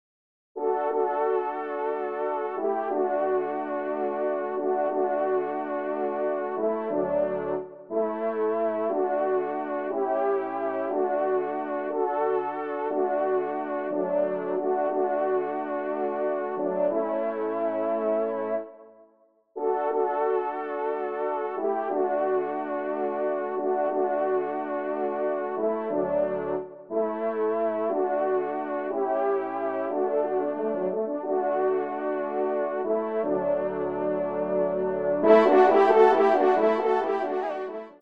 Genre : Musique Religieuse pour  Quatre Trompes ou Cors
ENSEMBLE